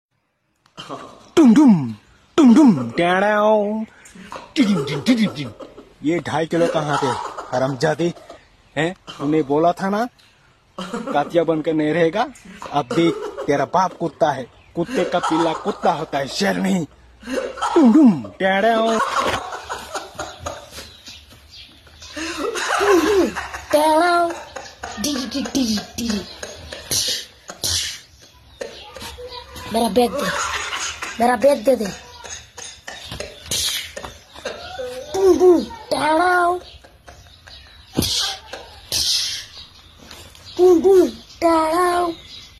dhum dhum tanau Meme Sound Effect